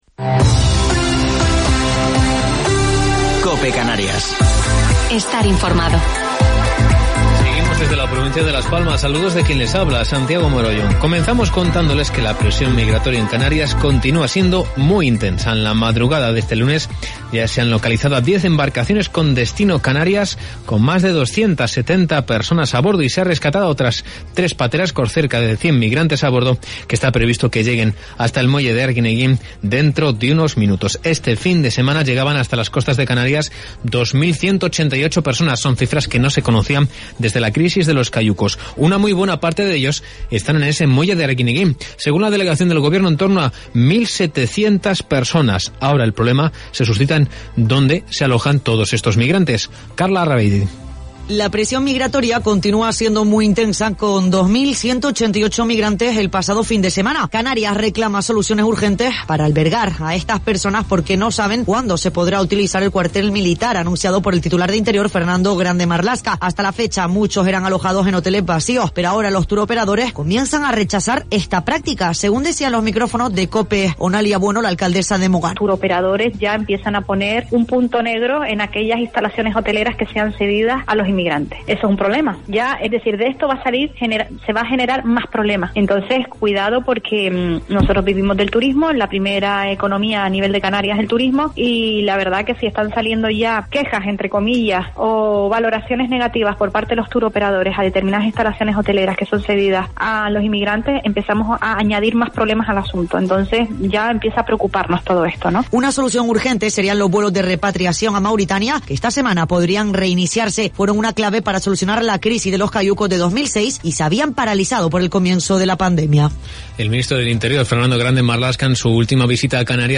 Informativo local 9 de Noviembre del 2020